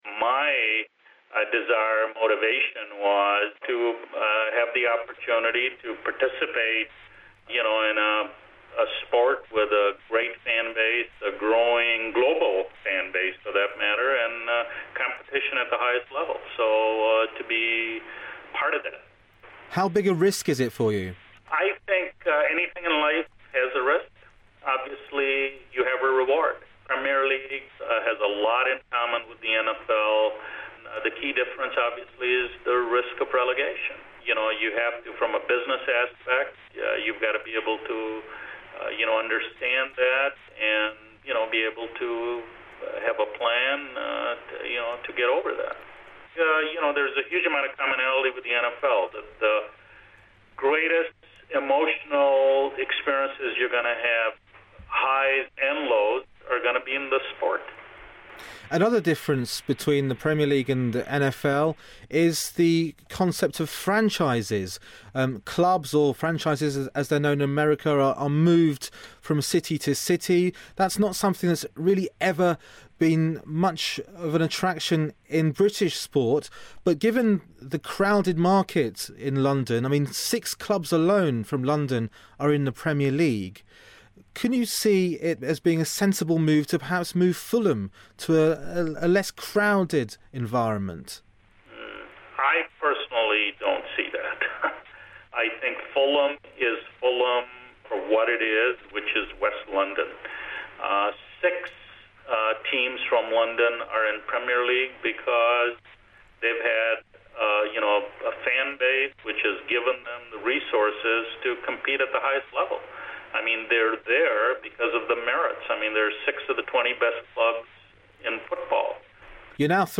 Interview with Shahid Khan
Includes Moustache chat. Broadcast on the World Football and Sportshour programmes on BBC World Service.